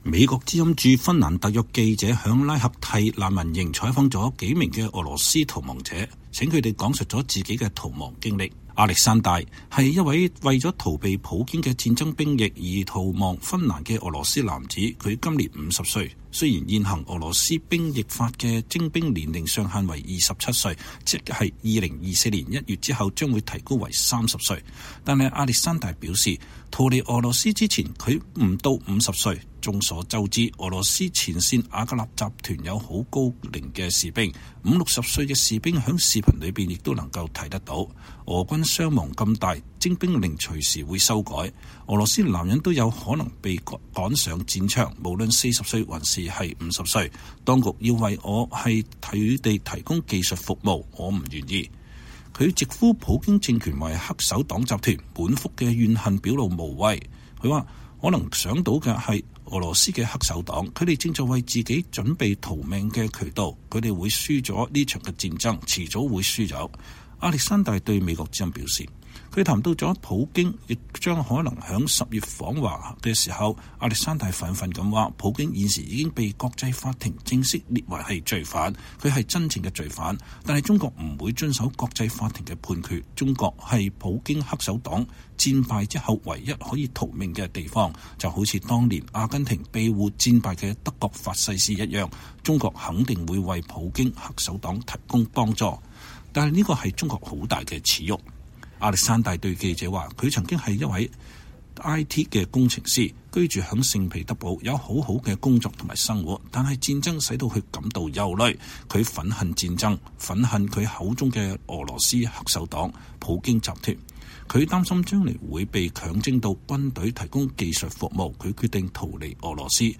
VOA獨家專訪: 躲避戰爭兵役逃亡芬蘭的俄羅斯人